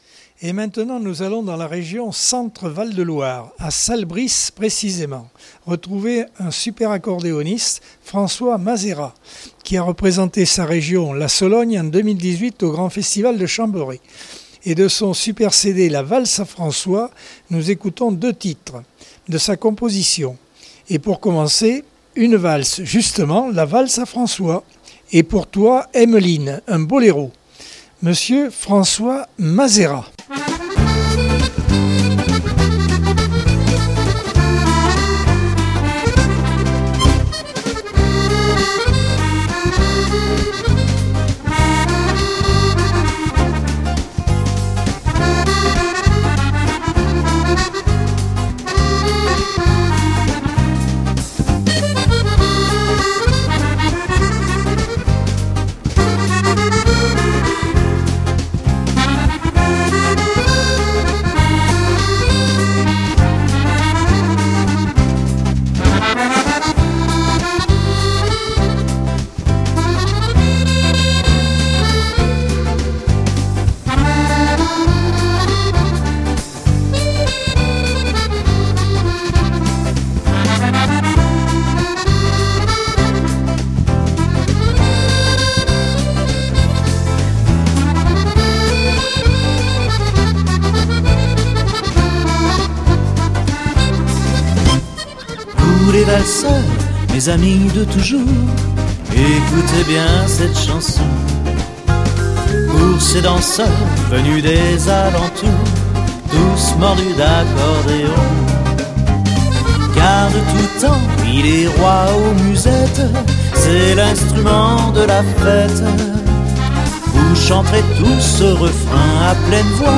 Accordeon 2022 sem 27 bloc 3.